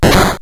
not_effective.wav